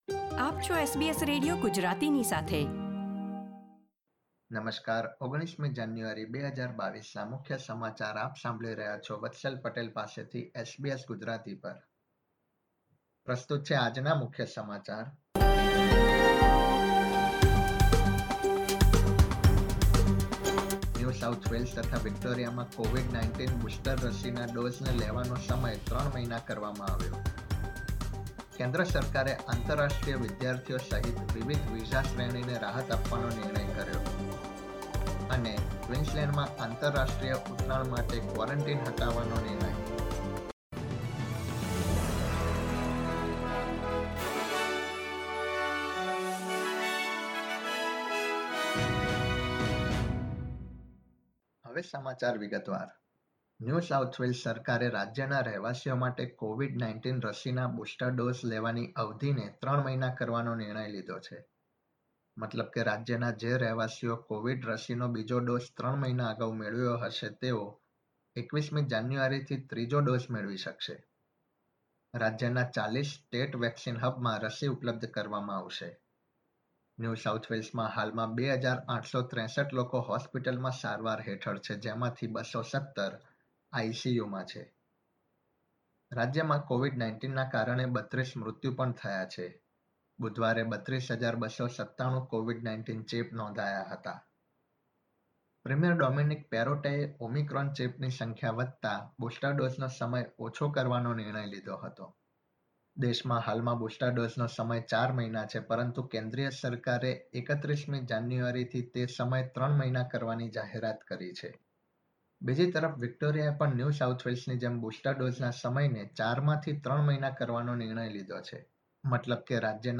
SBS Gujarati News Bulletin 19 January 2022